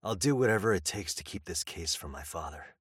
Pocket voice line - I'll do whatever it takes to keep this case from my father.